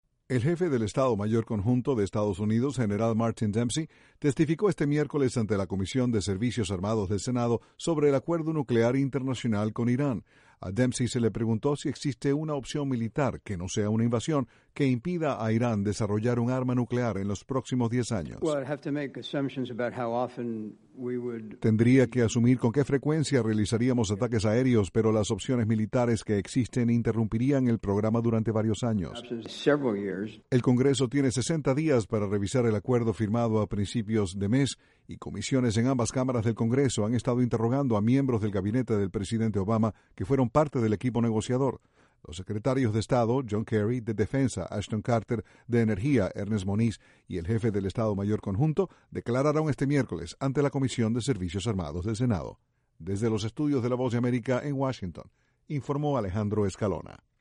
Miembros del gabinete del presidente Obama continúan testificando sobre el acuerdo nuclear internacional con Irán. Desde la Voz de América, Washington